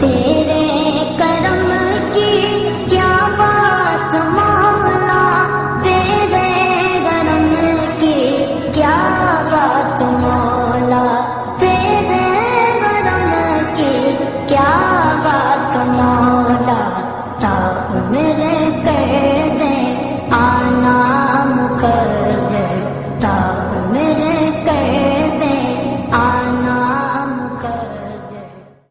Naat Ringtones